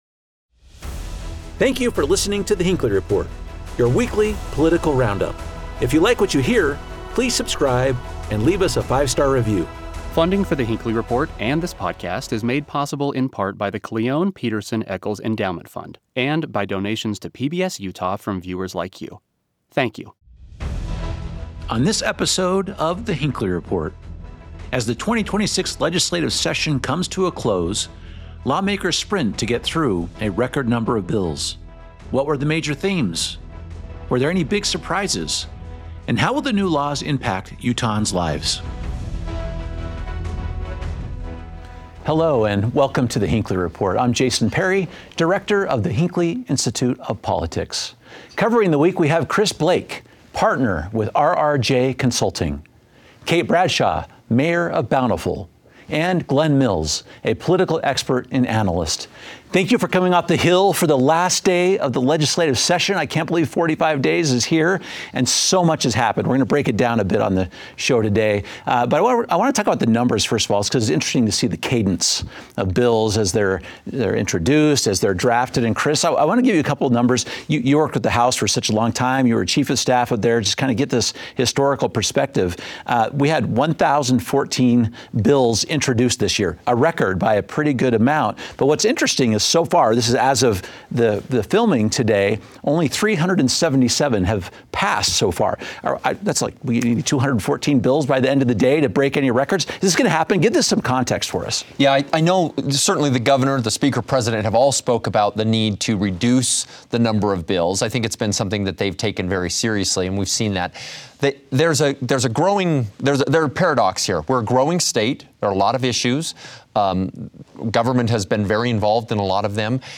Our panel discusses whether we achieve better policy with fewer bills.